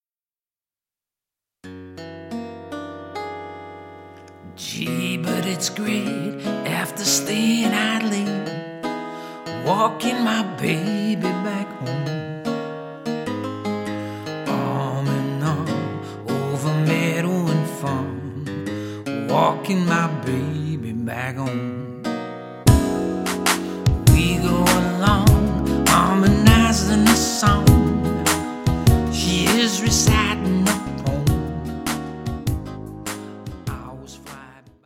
a highly experienced and professional two-piece band